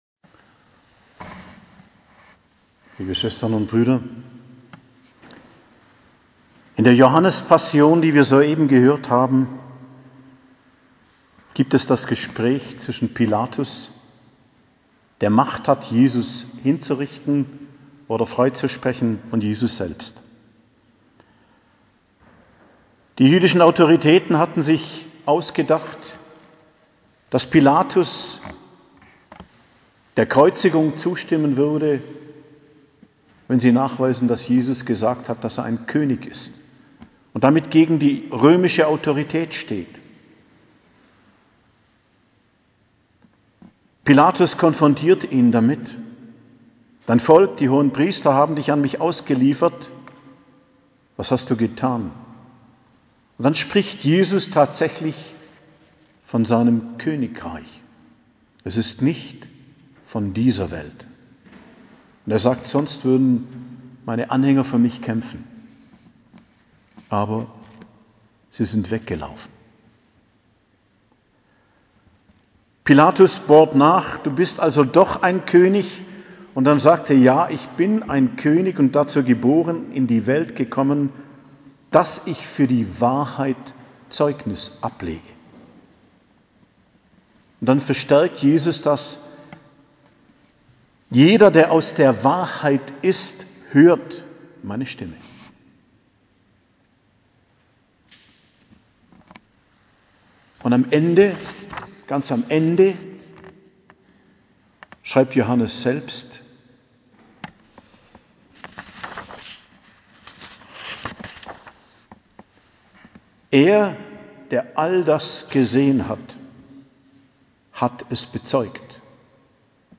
Predigt am Karfreitag, die Feier vom Leiden und Sterben Christi, 15.04.2022 ~ Geistliches Zentrum Kloster Heiligkreuztal Podcast